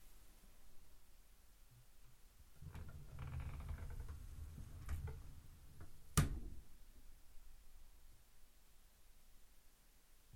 Slow/Open Top Drawer/1
Duration - 10 s Environment - Bedroom, absorption of curtains, carpet and bed. Description - Open, pulled slowly wooden drawer slides/rolls on rails. Record distance is at its highest